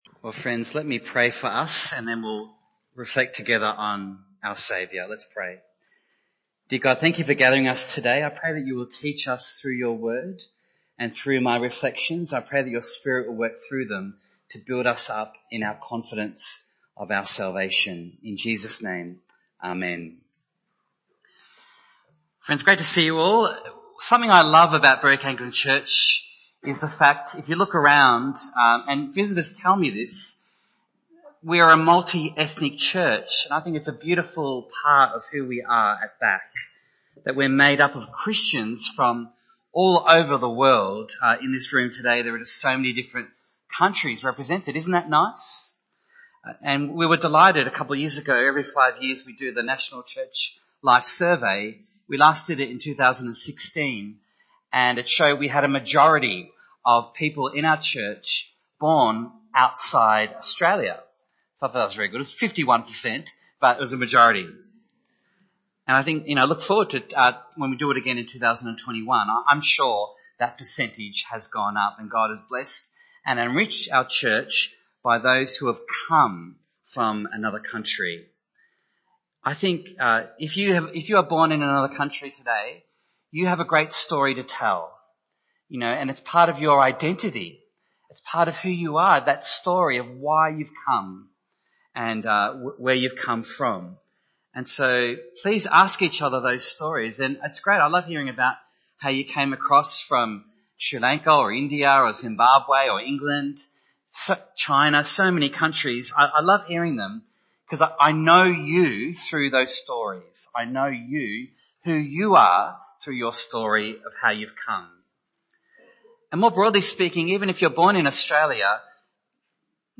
Passage: John 8:42-59 Service Type: Sunday Morning